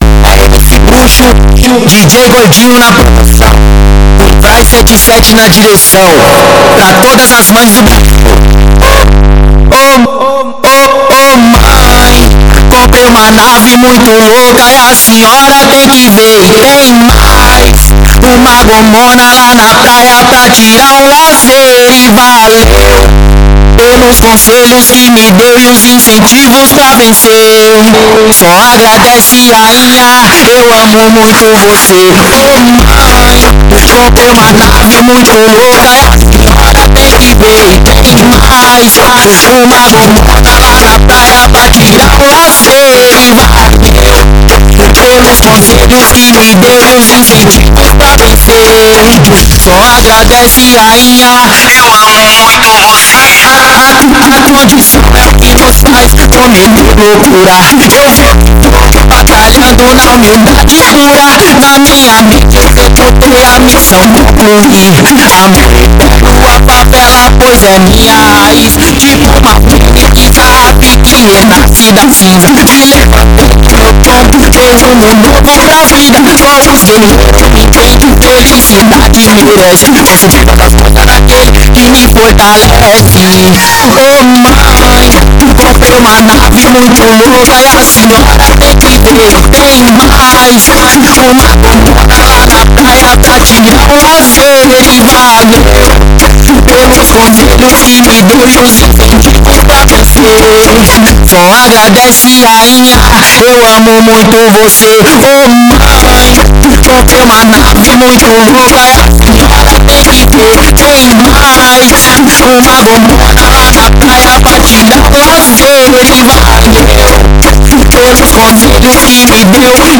Composição: funk.